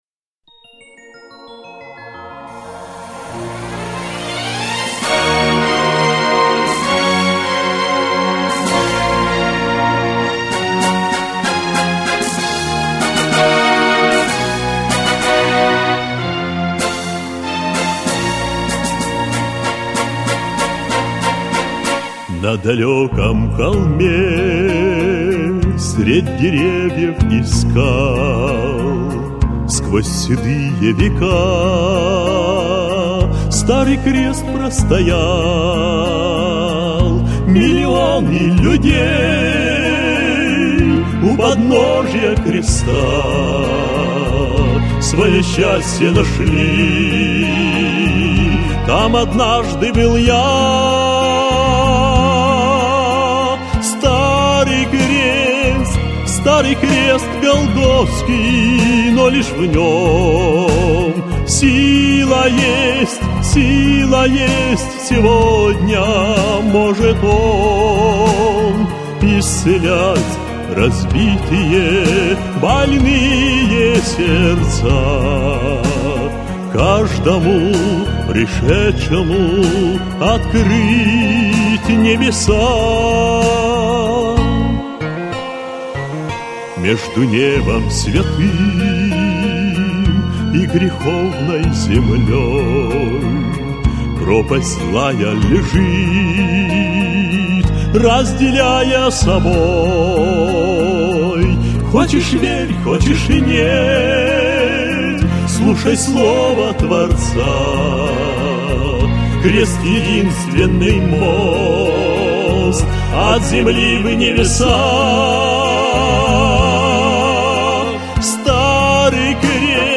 песня
540 просмотров 999 прослушиваний 64 скачивания BPM: 78